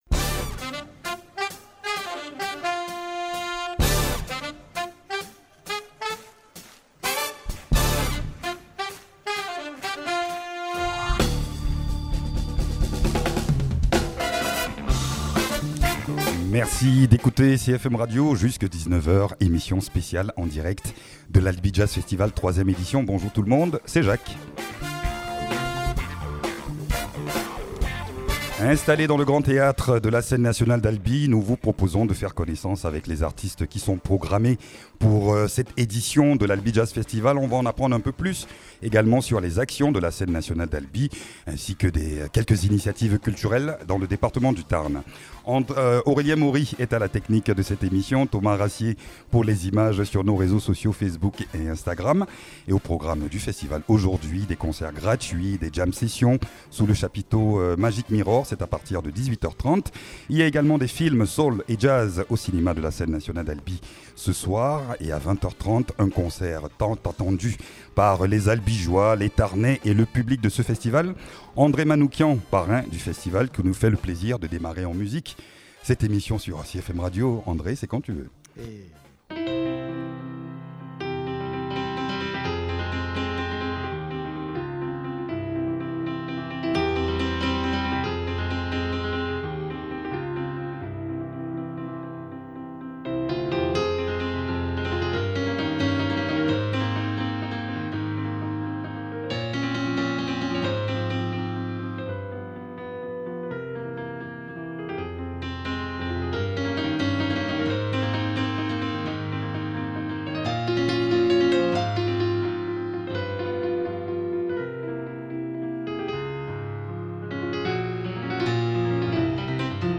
Avec en prime un live piano !